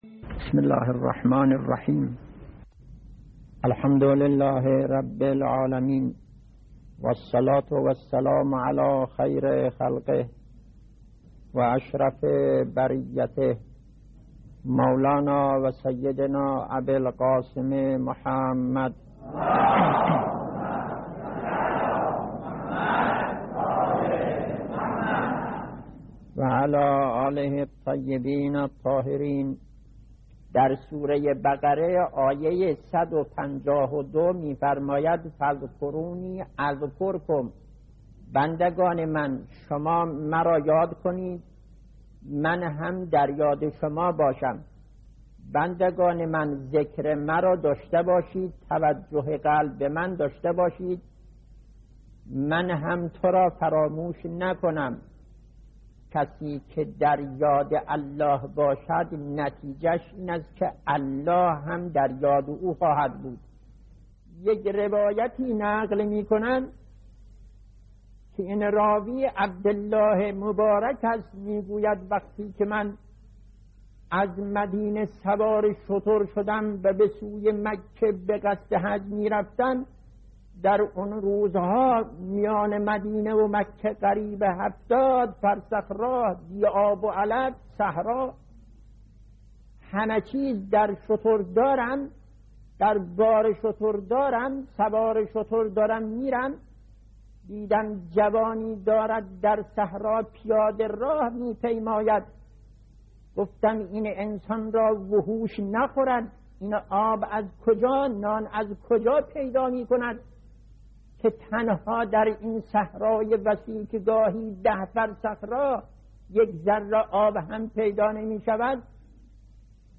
به گزارش پایگاه خبری 598، مرحوم آیت الله مشکینی در یکی از جلسات اخلاق خود به موضوع «توجه قلبی و یاد خدا» پرداخت که تقدیم شما فرهیختگان می شود.